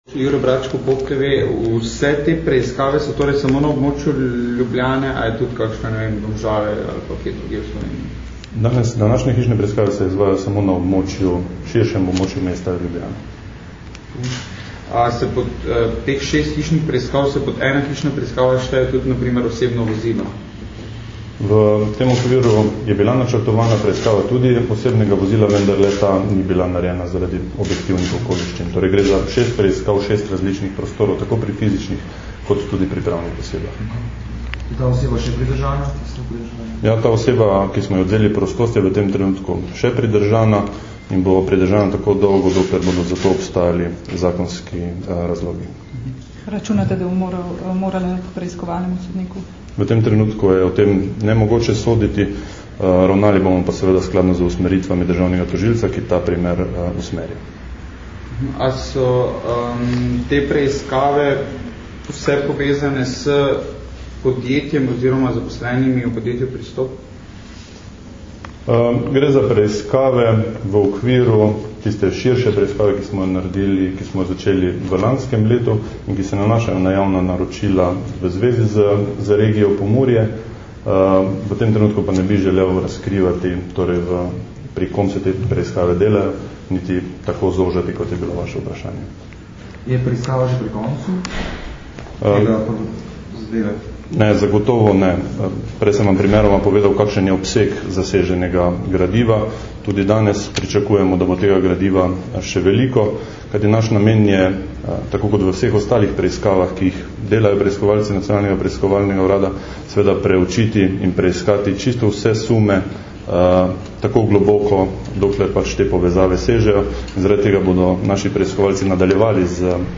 Nacionalni preiskovalni urad na območju policijske uprave Ljubljana preiskuje več sumov storitve kaznivih dejanj gospodarske kriminalitete in korupcije. Več informacij o tem smo predstavili na današnji izjavi za medije.
Novinarska vprašanja in odgovori (mp3)